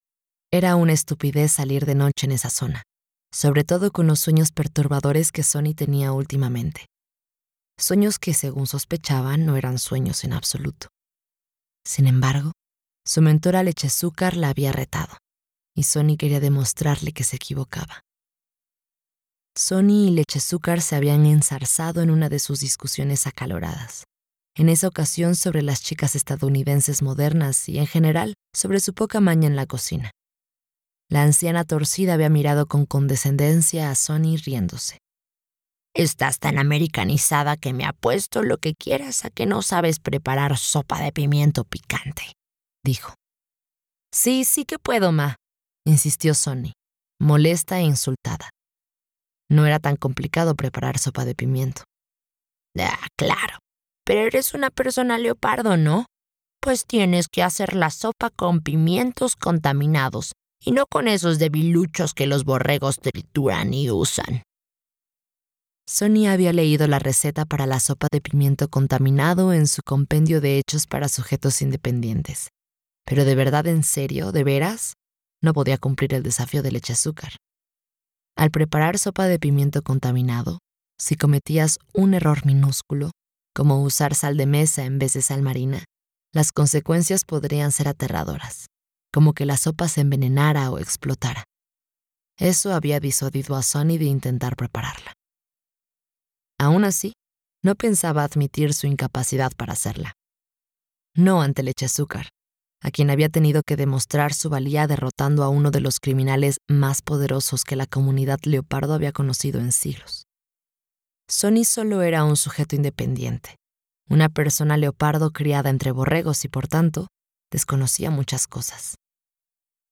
Audiolibro Guerrera Akata (Akata Warrior)